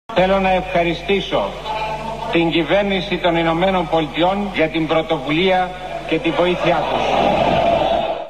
Κ. Σημίτης λίγες μέρες μετά τα Ίμια: Ευχαριστώ τους Αμερικανούς (από το βήμα της βουλής) wma file 194 Kb